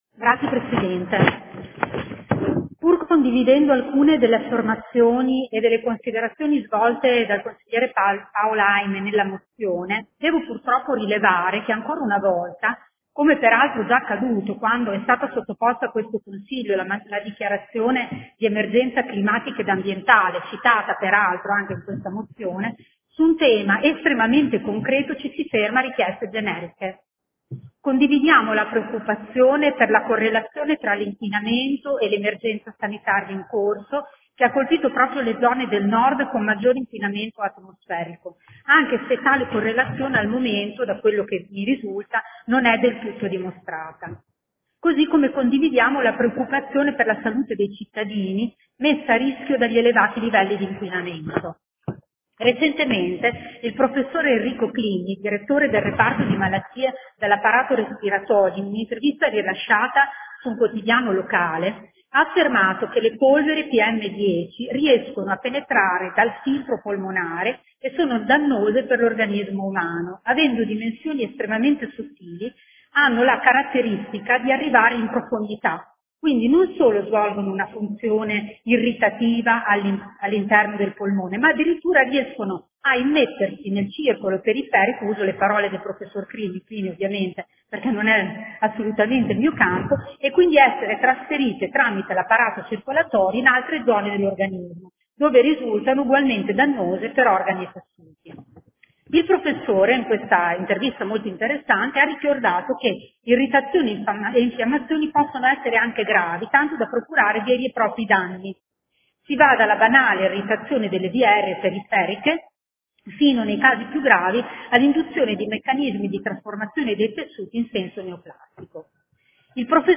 SEDUTA DEL 13/05/2020. DIBATTITO SU MOZIONE PROT. GEN. 124956